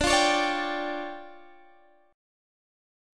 Listen to DmM11 strummed